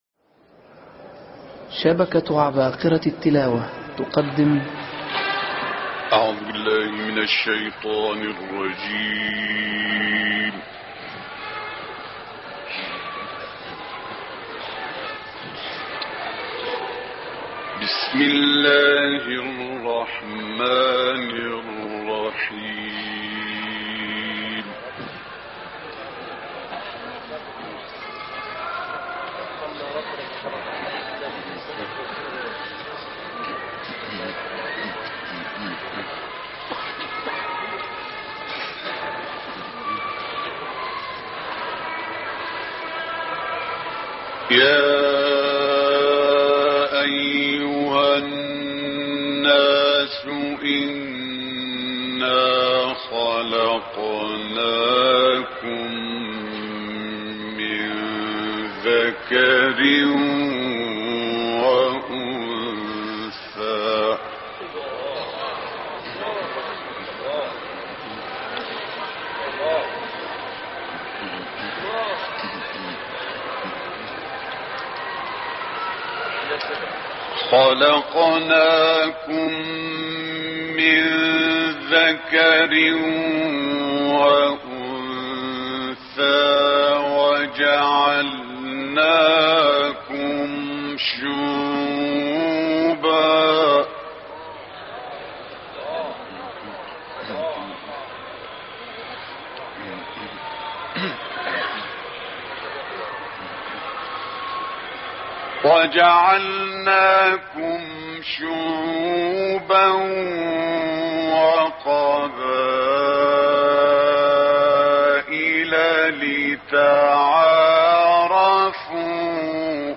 تلاوة رائعة بصوت القارئ المصري "الشيخ إبراهيم الشعشاعي"
أصدرت القناة التلغرامية التابعة للقارئ المصري "الشيخ إبراهيم الشعشاعي" تلاوة رائعة لآيات من سور "الحجرات"، و"ق" وقصار السور بصوت القارئ الراحل "الشعشاعی" قدّمها عام 1982 للميلاد في مسجد الامام الحسين(ع) في القاهرة.